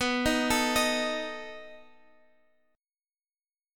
B7 Chord (page 3)
Listen to B7 strummed